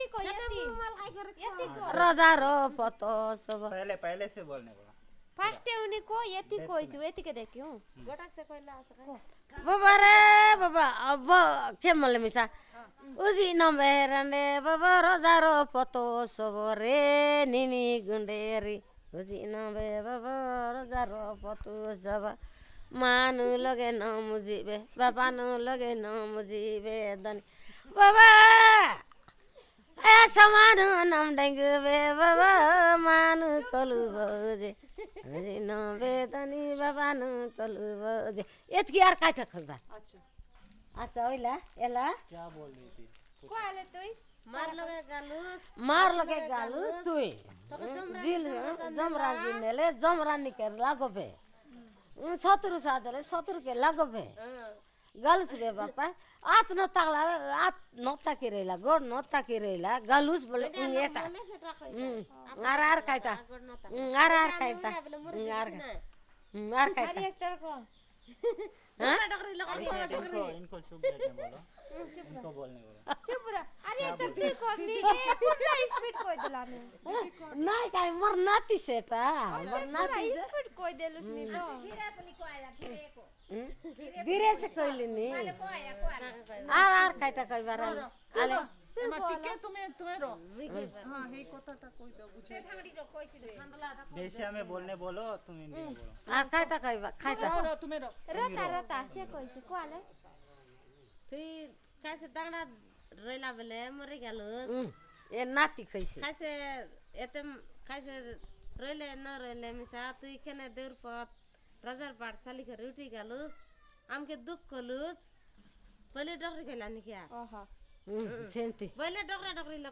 Perfomance of sad song